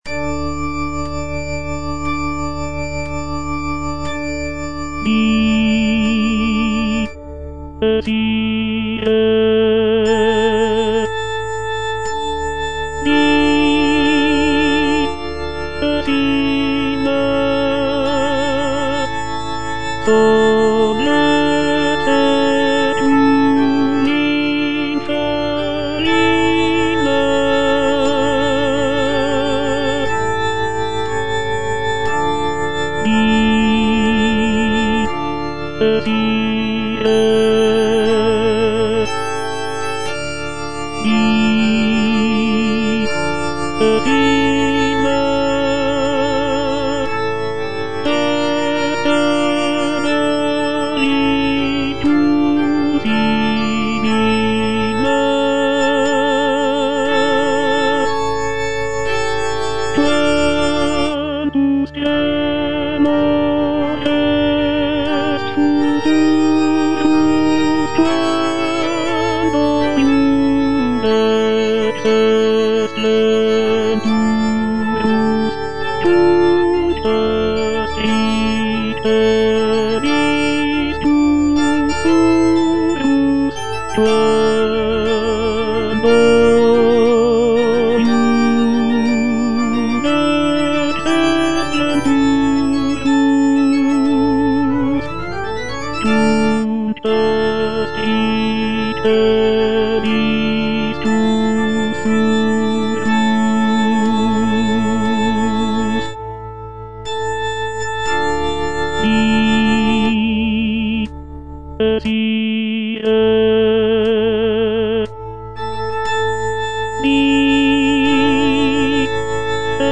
Tenor (Voice with metronome) Ads stop
is a sacred choral work rooted in his Christian faith.